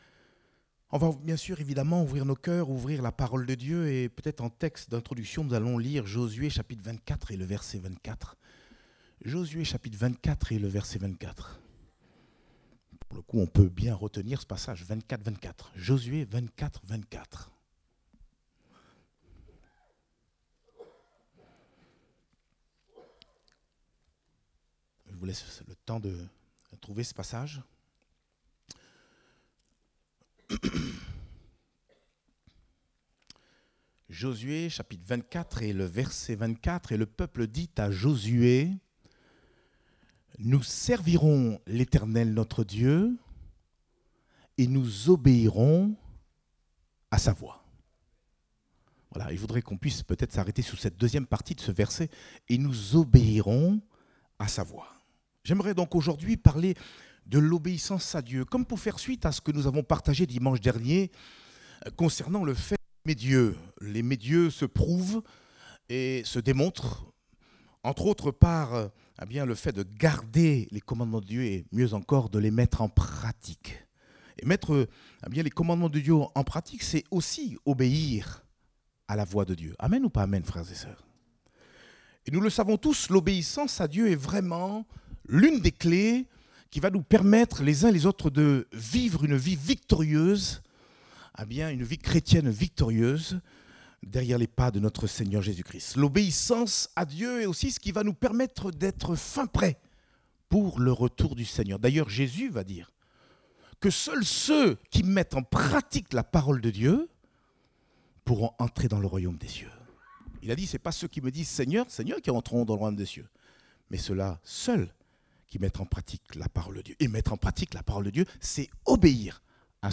Date : 19 novembre 2023 (Culte Dominical)